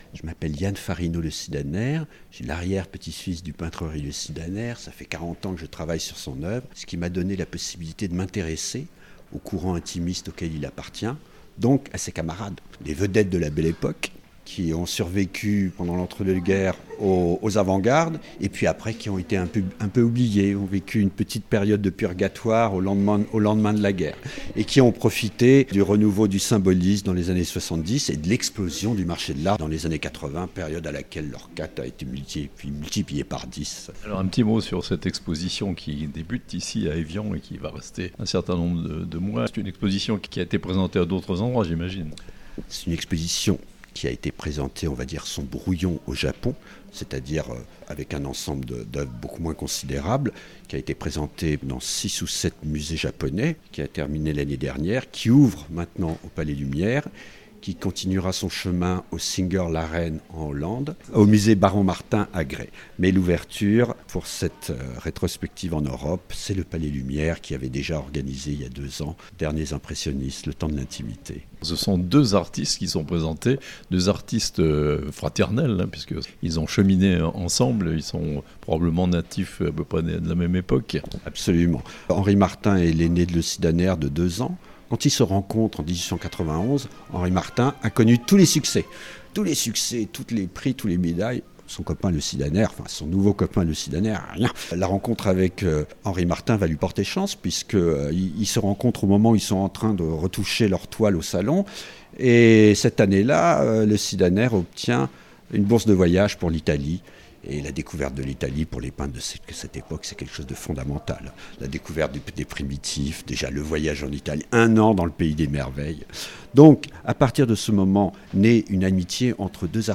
Evian : deux peintres pour une très belle exposition au Palais Lumière (interview)